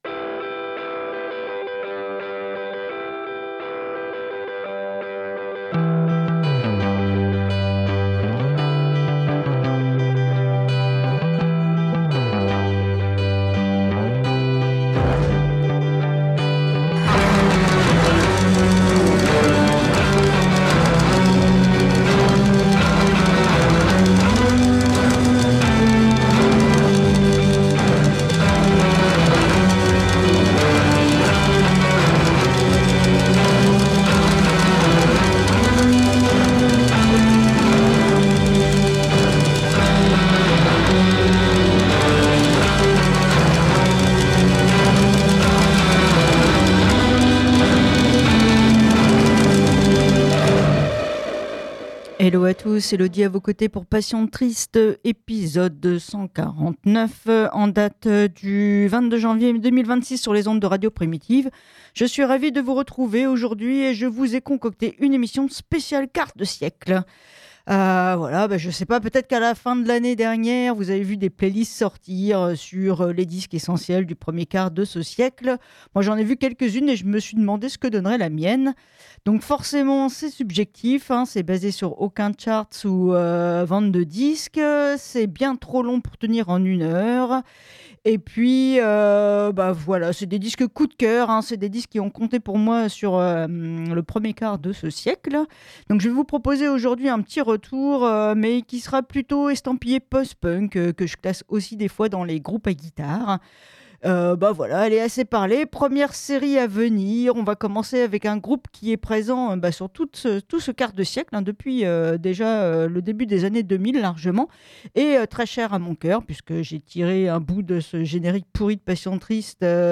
Emission spéciale 2000-2025 partie 1 : post punk avec :